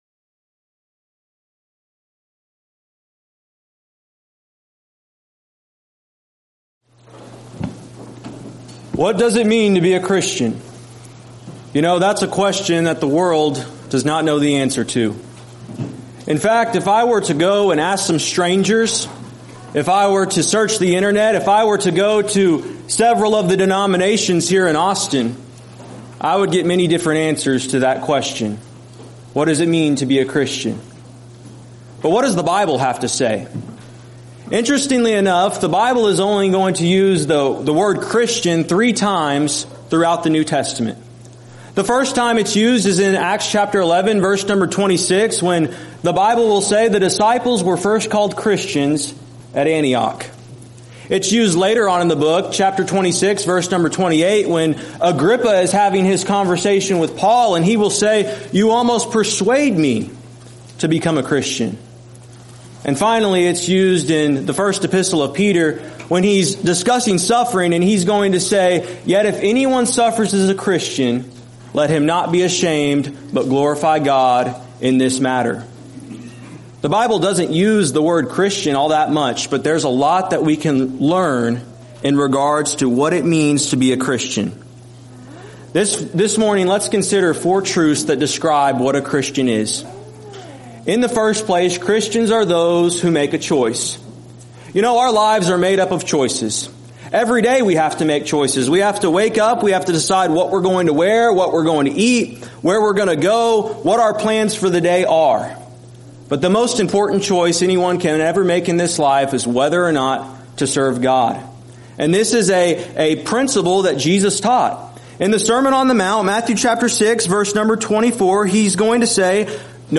Alternate File Link File Details: Series: Arise: Southwest Spiritual Growth Workshop Event: 7th Annual Arise: Southwest Spiritual Growth Workshop Theme/Title: Arise with Conviction!